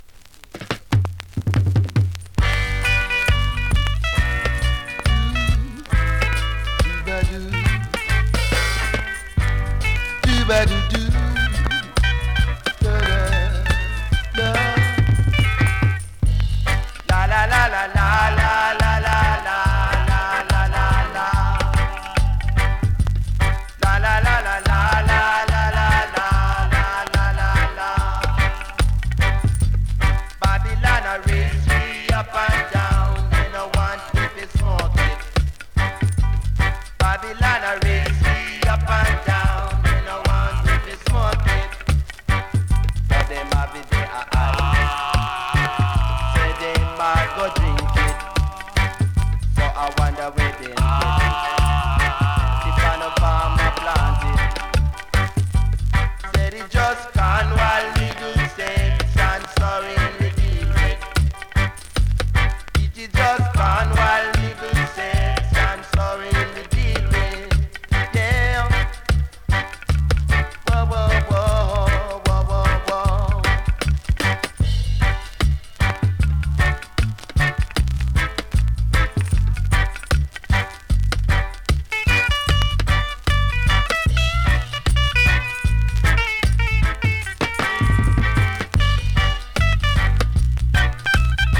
2023 NEW IN!!SKA〜REGGAE!!
スリキズ、ノイズそこそこありますが